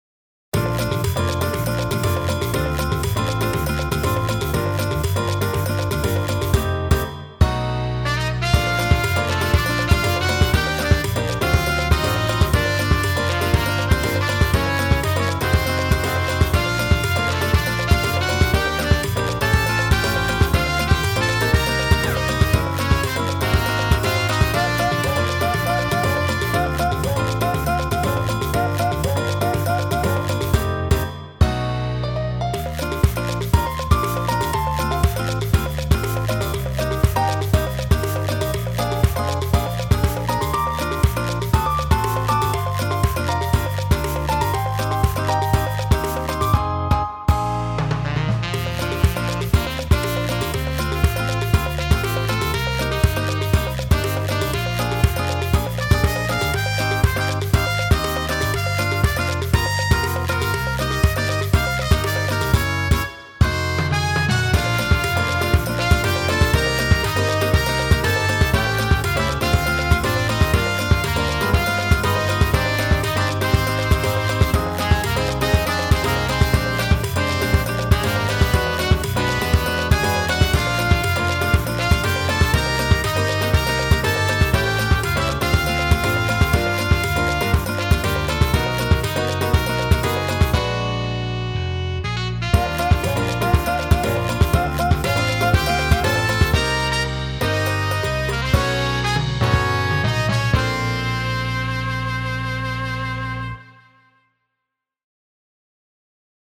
ラテン系
サンバ｜海｜元気｜楽しい